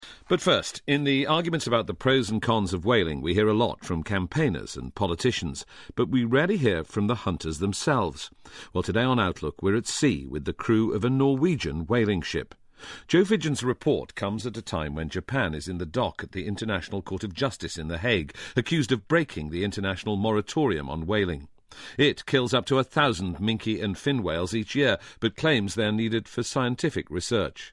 【英音模仿秀】捕鲸的争论 听力文件下载—在线英语听力室